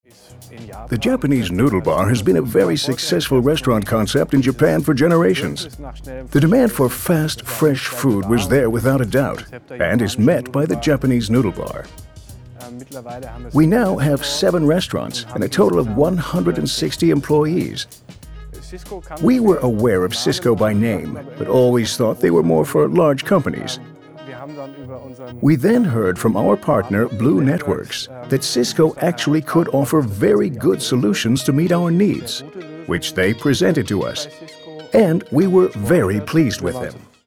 Die fertigen deutschen Filme wurden dann in den USA, in München und auch bei uns im Studio englisch synchronisiert.
Englische Vertonung – Native Speaker 1: